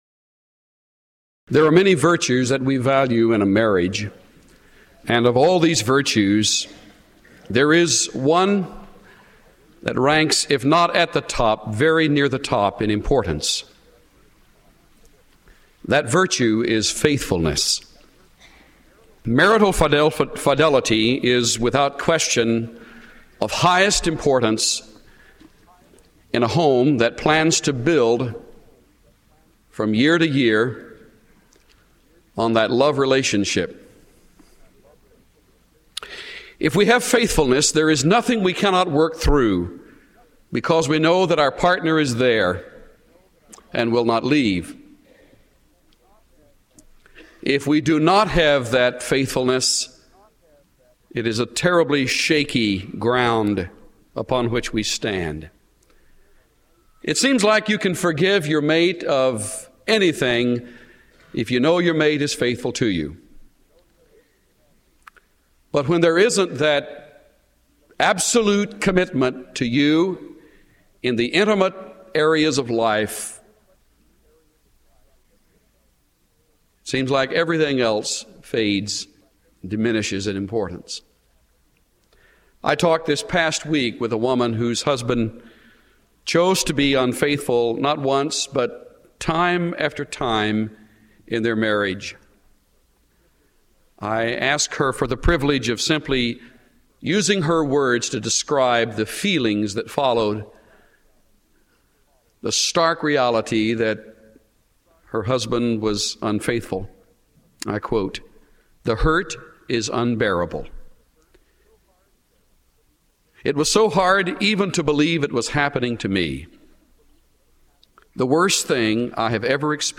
Listen to Chuck Swindoll’s overview of Hosea in his audio message from the Classic series God’s Masterwork.